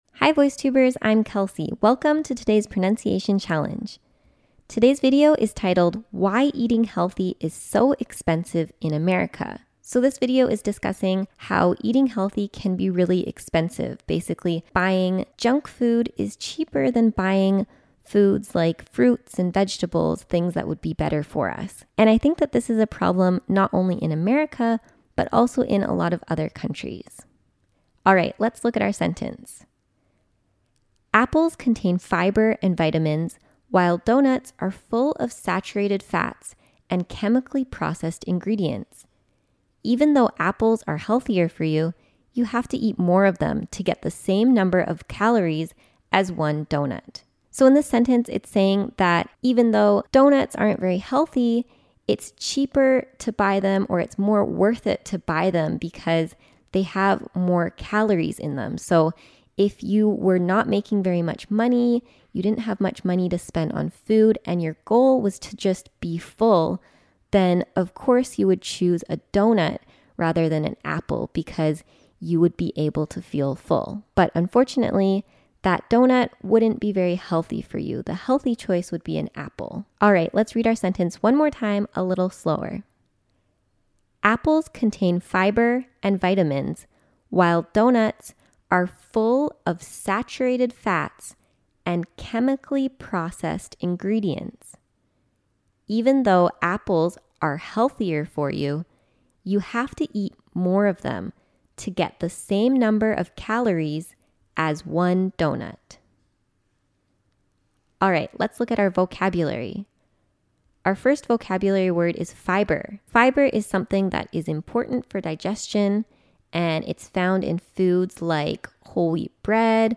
台湾籍旅美老师今天交给我们的句子是：